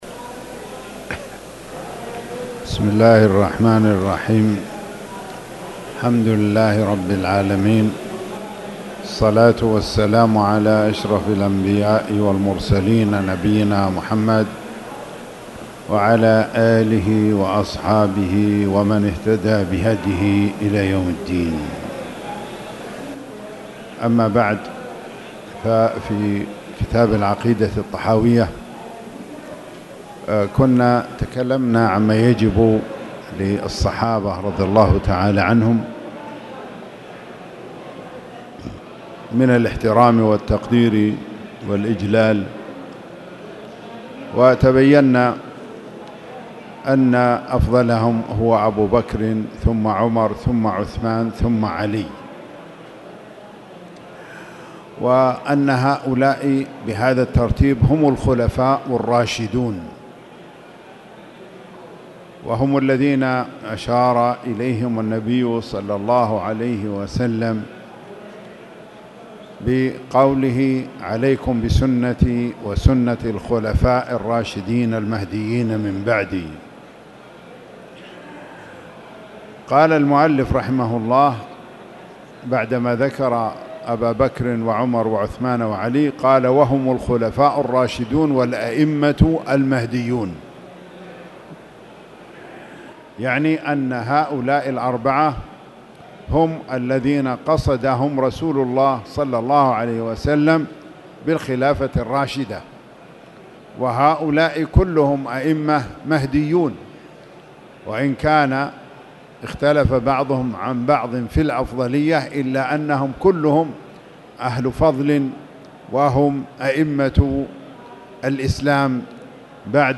تاريخ النشر ١٣ ربيع الأول ١٤٣٨ هـ المكان: المسجد الحرام الشيخ